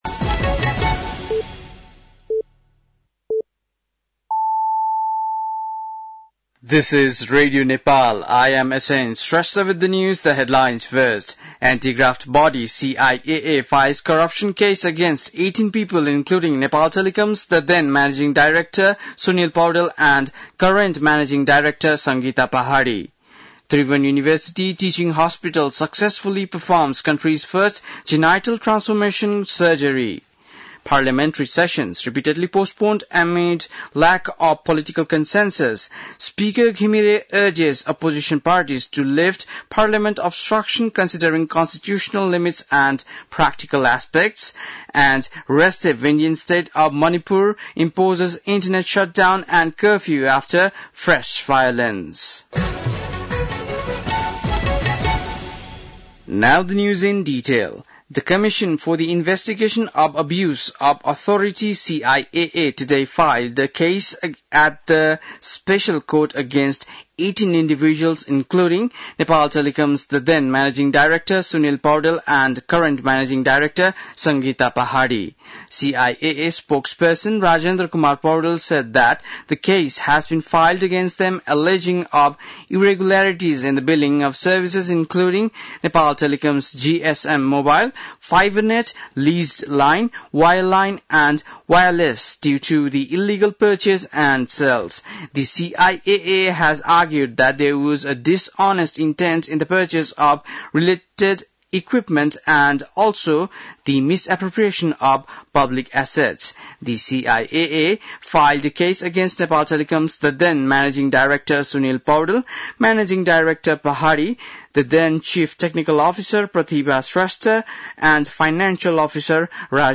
बेलुकी ८ बजेको अङ्ग्रेजी समाचार : २५ जेठ , २०८२
8-pm-english-news-2-25-.mp3